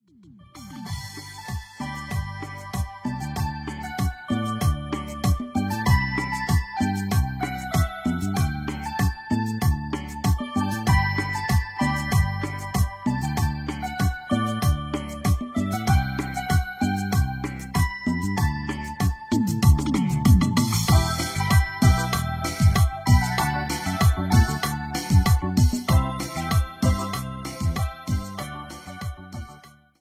99-Tex-Mex-1.mp3